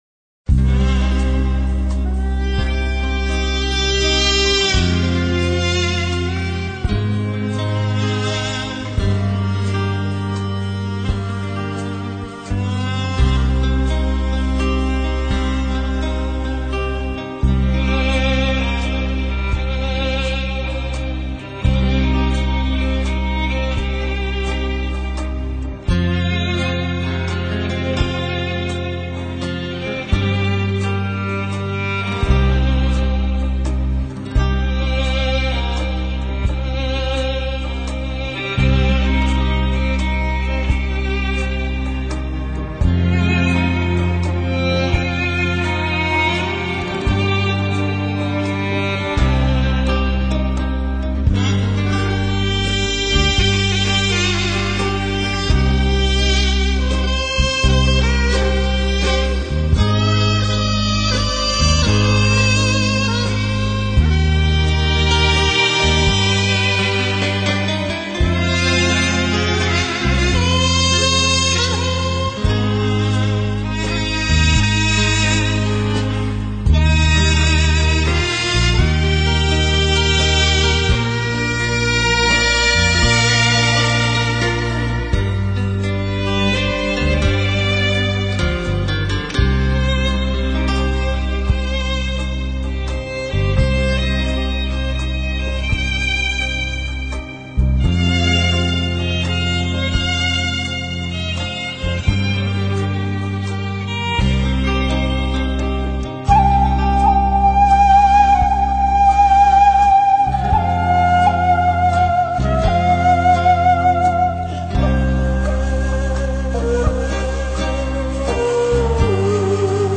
대금, 피리합주곡